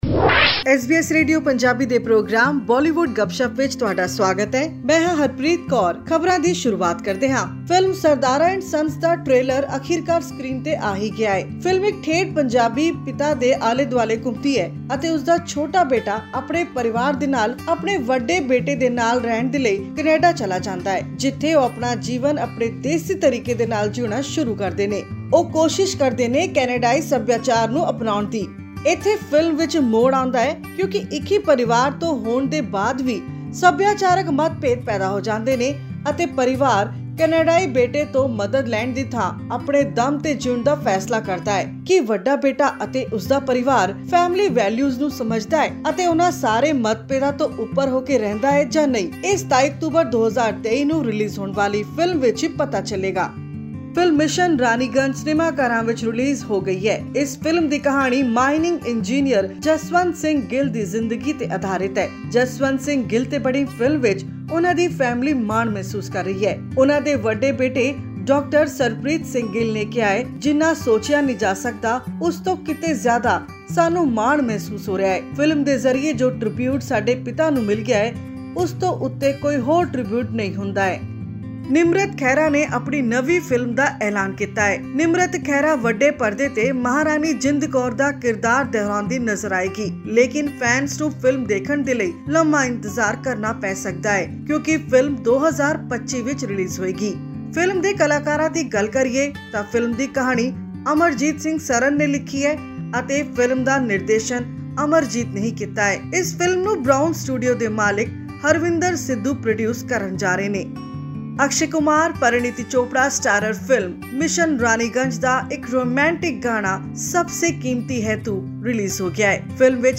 In the highly awaited biographical film, Punjabi heart throb Nimrat Khaira is all set to portray the role of legendary Maharani Jind Kaur, a fearless and iconic figure in Sikh history and the last queen of Punjab. This and more in our weekly news segment of Bollywood Gupshup on upcoming movies and songs.